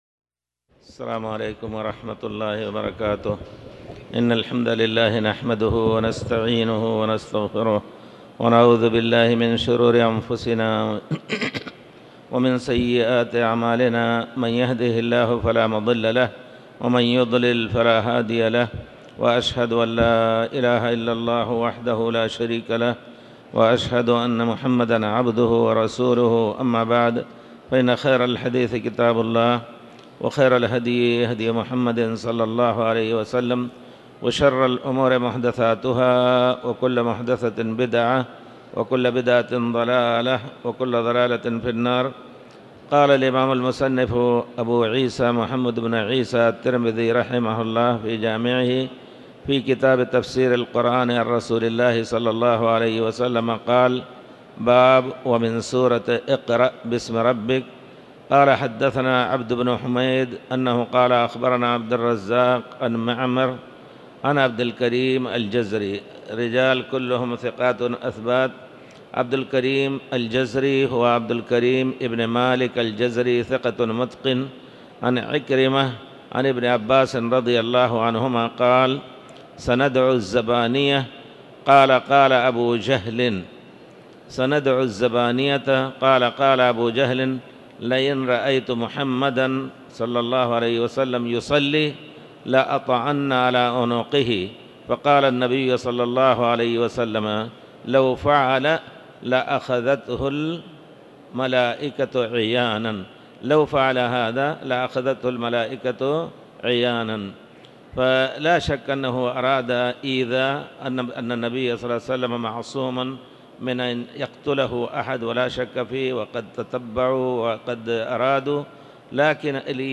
تاريخ النشر ٦ جمادى الأولى ١٤٤٠ هـ المكان: المسجد الحرام الشيخ